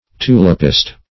Search Result for " tulipist" : The Collaborative International Dictionary of English v.0.48: Tulipist \Tu"lip*ist\, n. A person who is especially devoted to the cultivation of tulips.